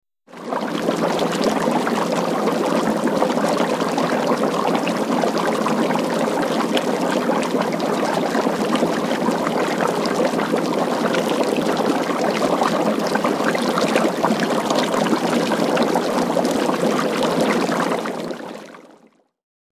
Boiling Liquid; Bubbles 3; A Medium, Rolling Liquid Boil; Consistency Of Water, Close Perspective.